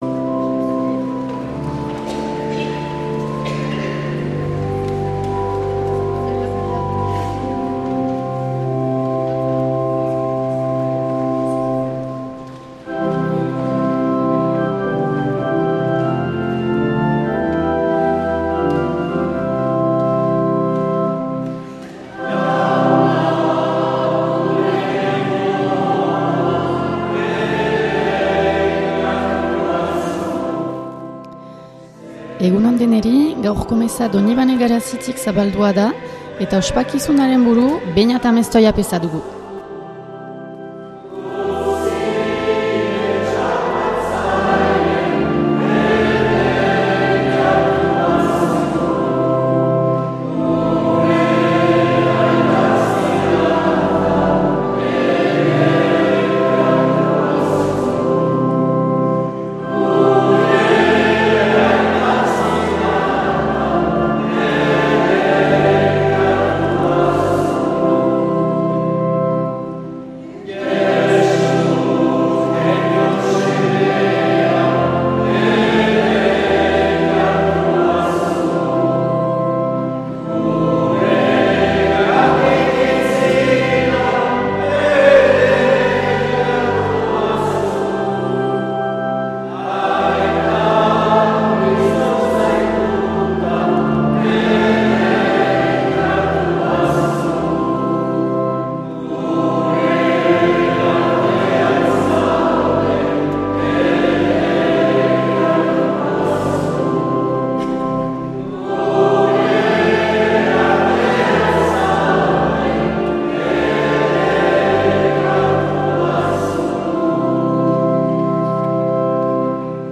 2025-10-12 Urteko 28. Igandea C - Garazi
Accueil \ Emissions \ Vie de l’Eglise \ Célébrer \ Igandetako Mezak Euskal irratietan \ 2025-10-12 Urteko 28.